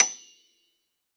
53q-pno28-F6.aif